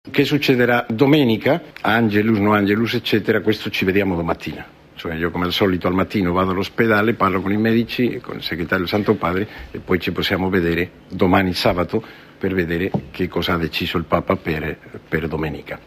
Mówi rzecznik Watykanu Navarro-Valls